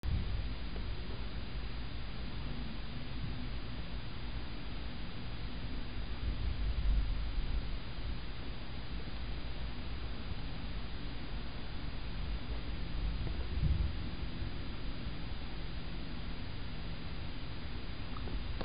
Female Cardinal At Our Bird Feeder in Canada